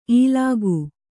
♪ īlāgu